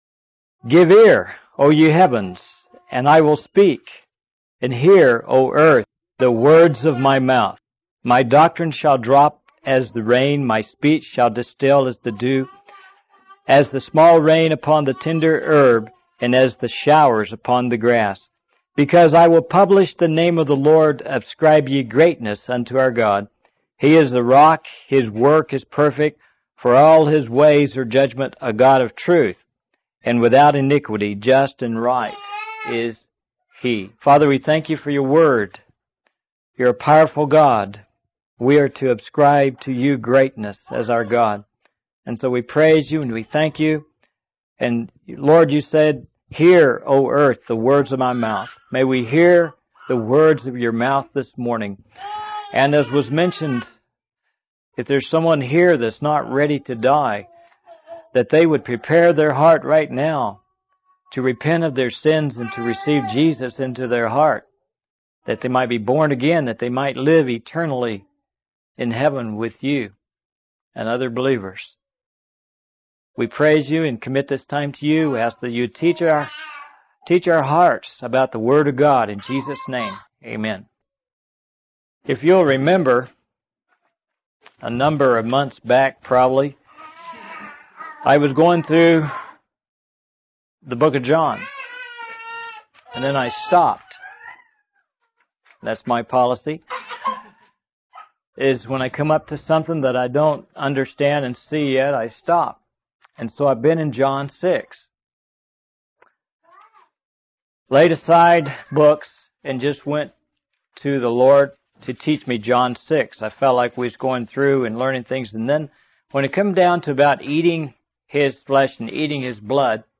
In this sermon, the speaker reflects on the power of the Word of God, comparing it to a hot loaf of bread that brings nourishment and satisfaction. He emphasizes that the Word of God brings life to dead areas and hearts, just as Jesus brought life to the disciples after His resurrection. The speaker encourages believers to always share the Word of God when witnessing to others, as the Spirit of God works through His Word.